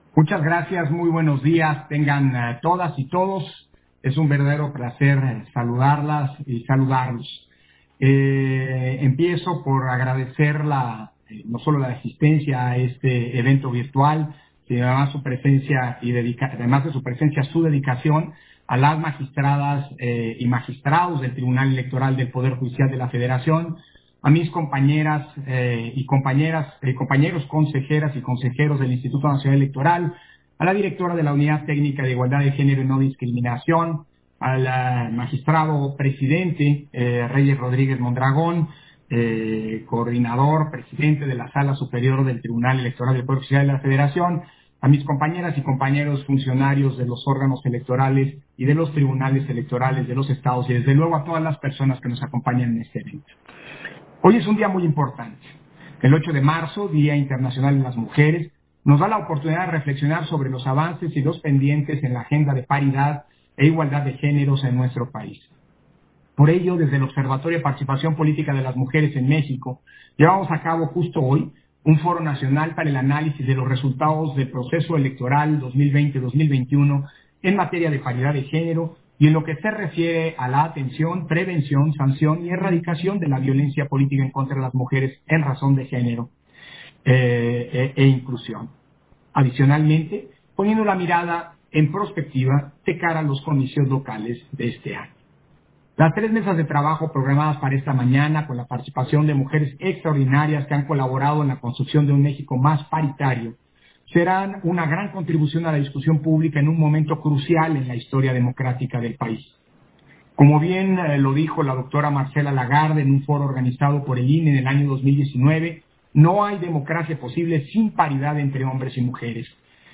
080322_AUDIO_INTERVENCIÓN-CONSEJERO-PDTE.-CÓRDOVA-INAUGURACIÓN-FORO-NACIONAL - Central Electoral